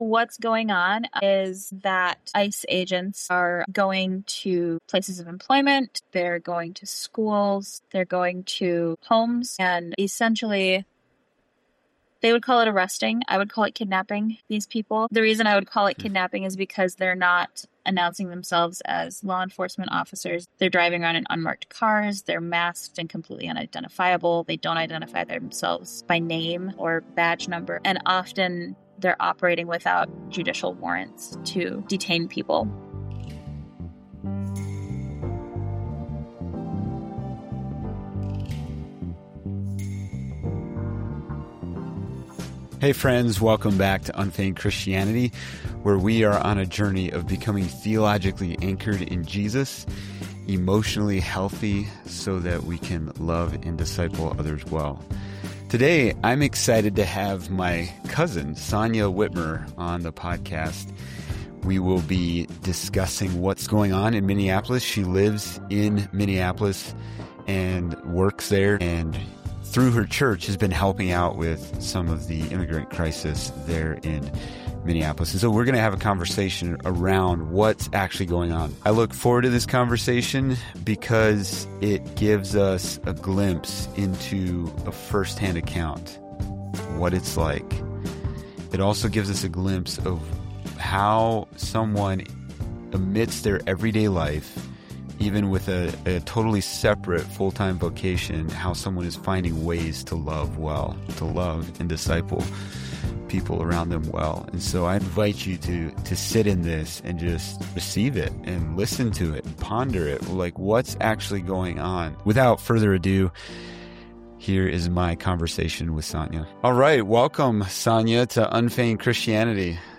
What’s Really Happening in Minneapolis? A Firsthand Conversation